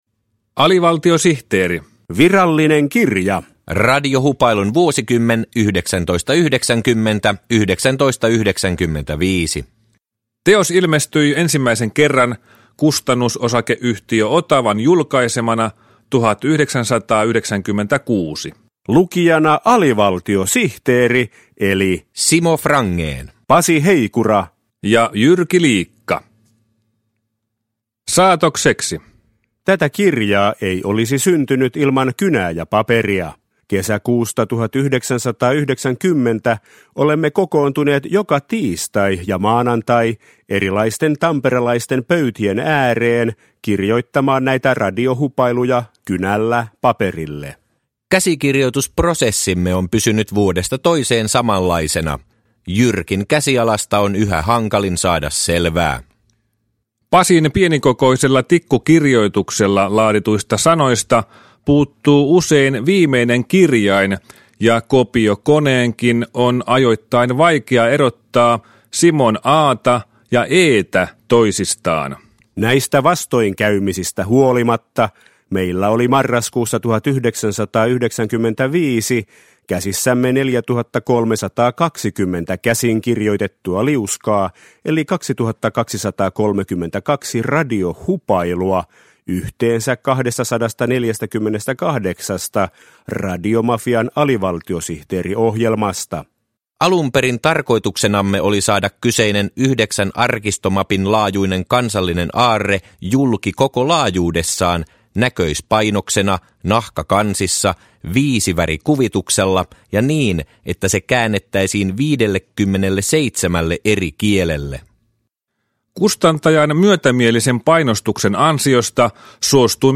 Alivaltiosihteeri Virallinen kirja – Ljudbok – Laddas ner